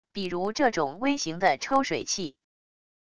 比如这种微型的抽水器wav音频